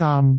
speech
syllable
pronunciation
daam6.wav